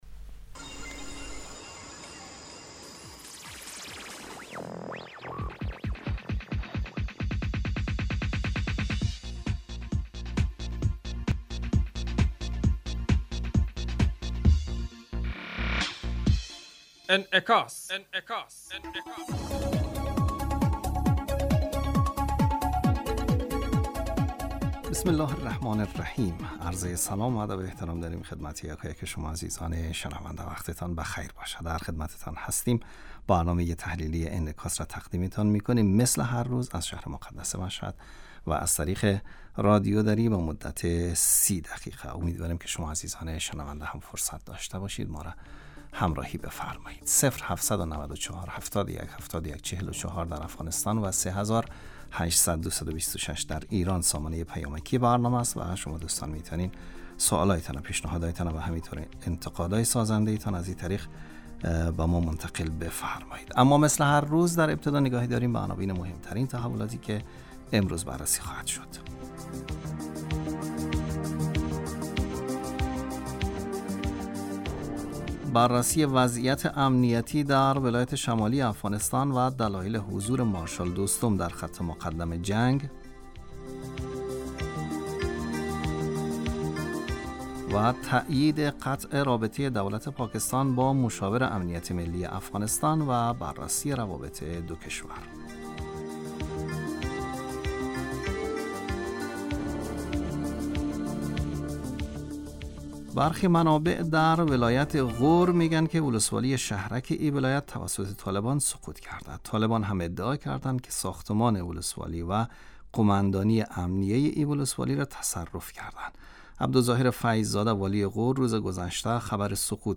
بررسی وضعیت امنیتی در ولایات شمالی افغانستان و دلایل حضورمارشال دوستم در خط مقدم جنگ. تائید قطع رابطه دولت پاکستان با مشاور امنیت ملی افغانستان و بررسی روابط دوکشور. برنامه انعکاس به مدت 30 دقیقه هر روز در ساعت 12:05 ظهر (به وقت افغانستان) بصورت زنده پخش می شود. این برنامه به انعکاس رویدادهای سیاسی، فرهنگی، اقتصادی و اجتماعی مربوط به افغانستان و تحلیل این رویدادها می پردازد.